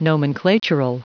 Prononciation du mot nomenclatural en anglais (fichier audio)
Prononciation du mot : nomenclatural